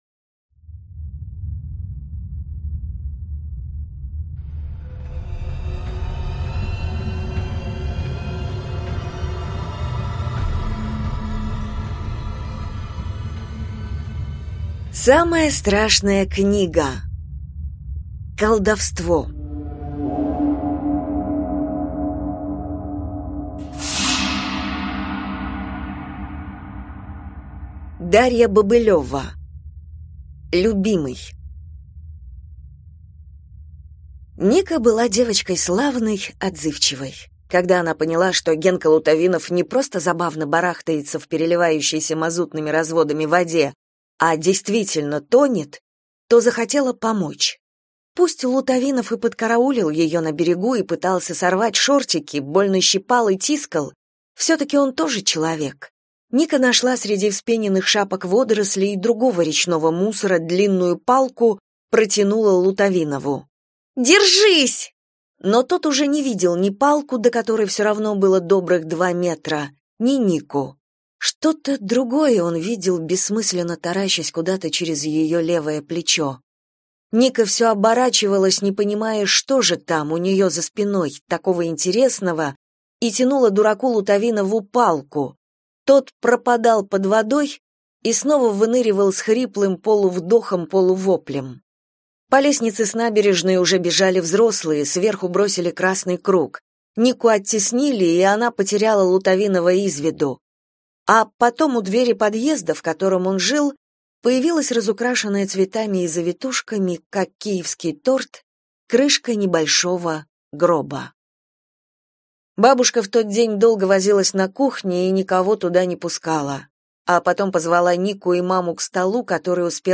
Аудиокнига Колдовство | Библиотека аудиокниг